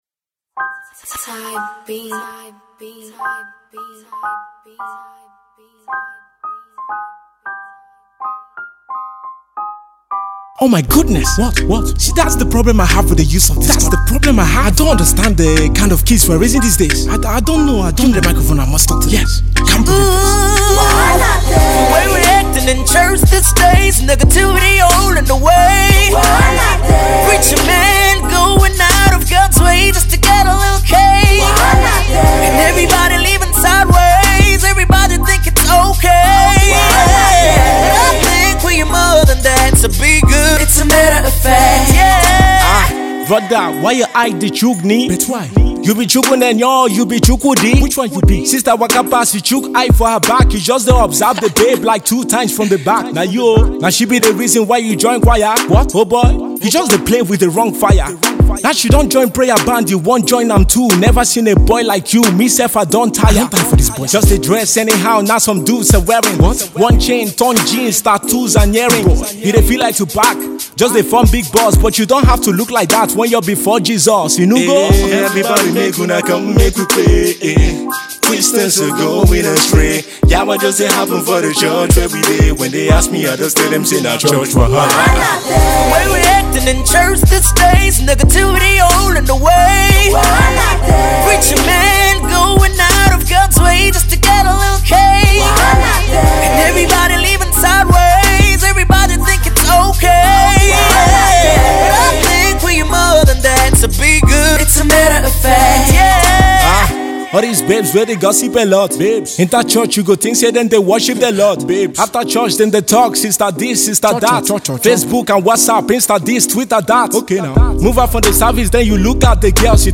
gospel/inspirational